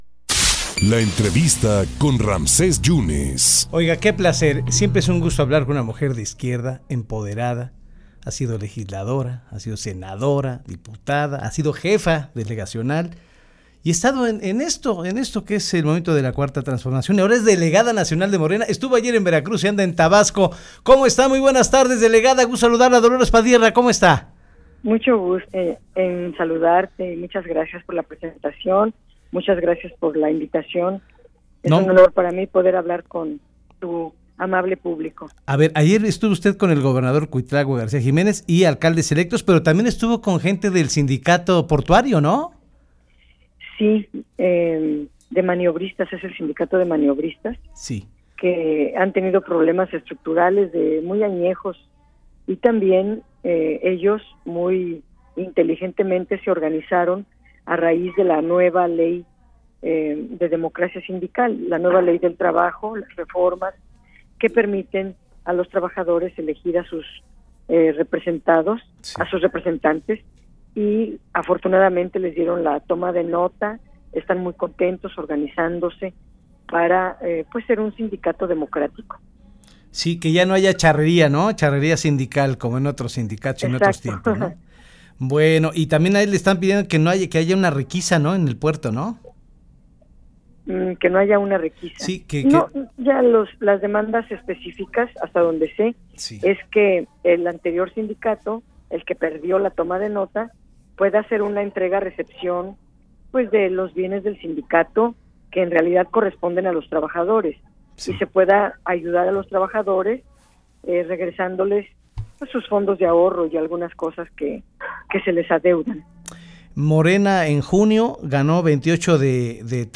21-10-27-ENTREVISTA-DOLORES-PADIERNA-MD-OK.mp3